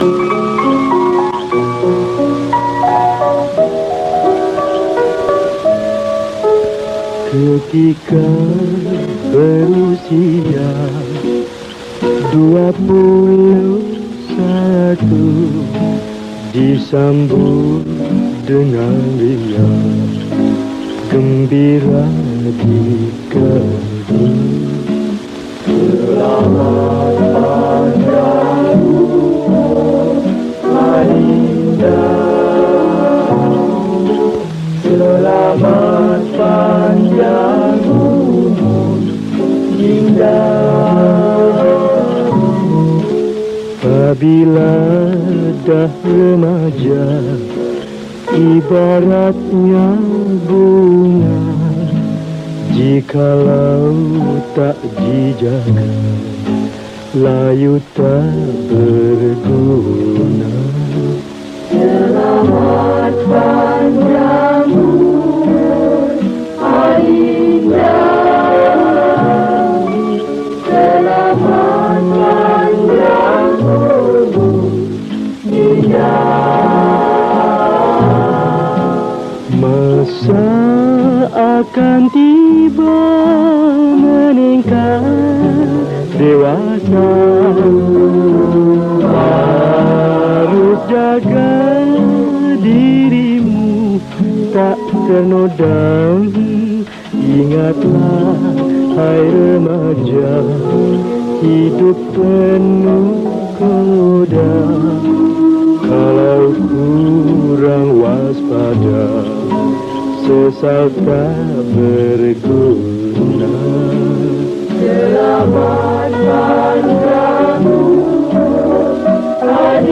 Malay Song
Skor Angklung